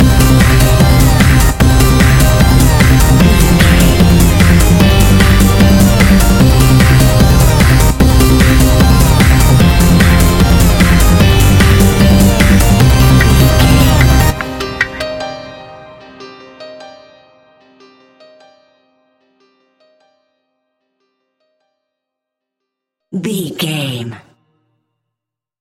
Sounds Like Trance 15 Sec Mix.
Aeolian/Minor
Fast
energetic
hypnotic
drum machine
synthesiser
piano
acid house
uptempo
synth leads
synth bass